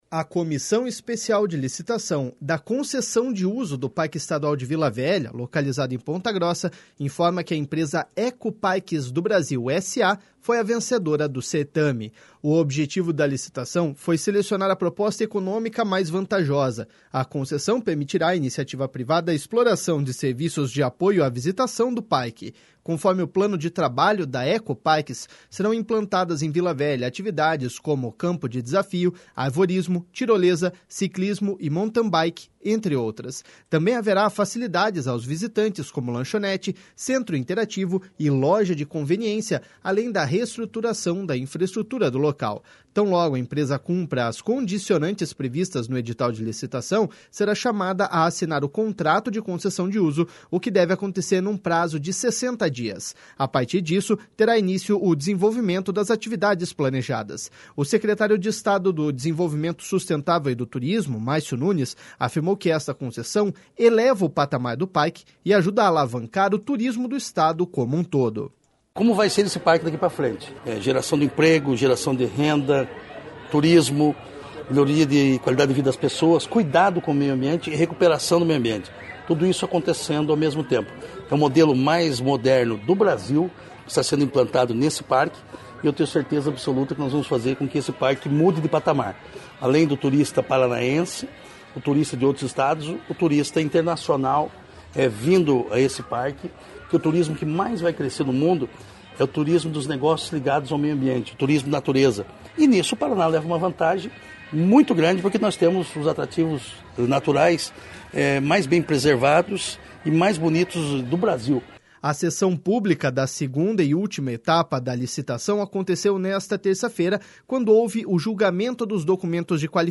O secretário de Estado do Desenvolvimento Sustentável e do Turismo, Márcio Nunes, afirmou que esta concessão eleva o patamar do parque e ajuda a alavancar o turismo do Estado, como um todo.// SONORA MARCIO NUNES.//